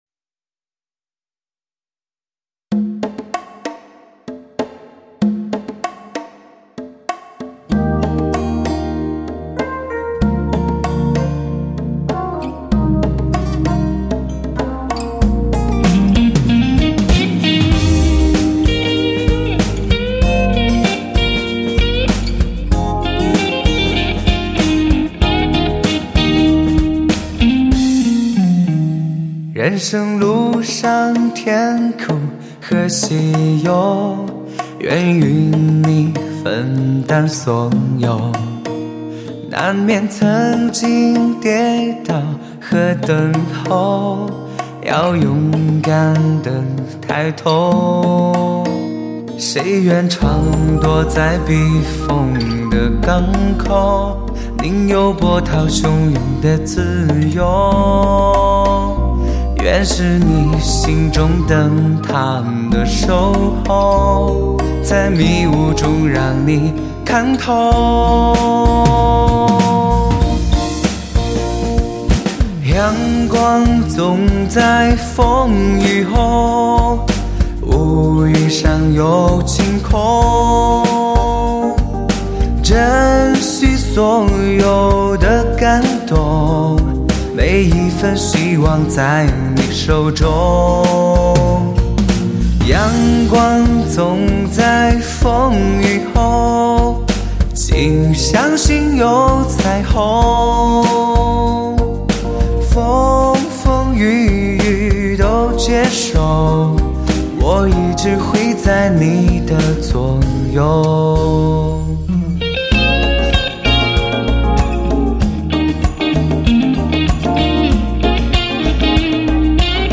荣获法兰克福展最佳音效奖，澳大利亚音乐节最佳唱片！
并且人声收录方面采用了48BIT/192KHZ高采样录音，